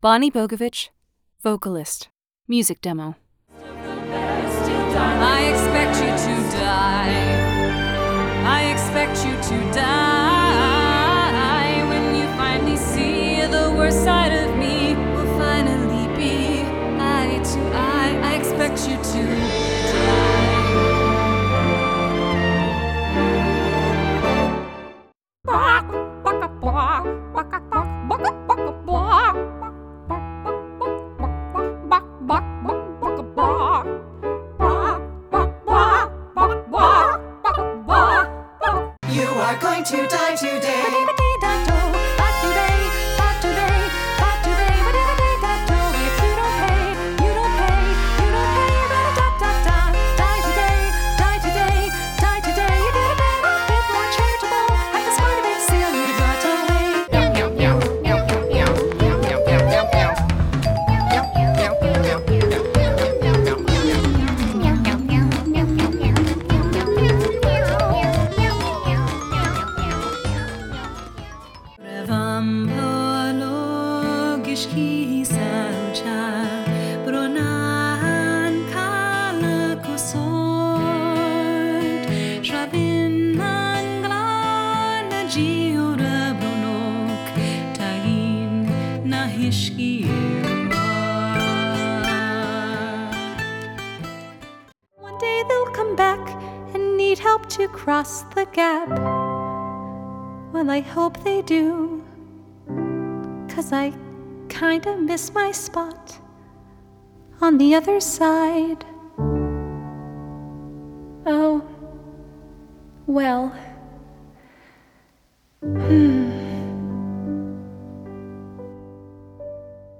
Music Demo - Singing - Vocalist - various styles